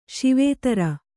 ♪ śivētara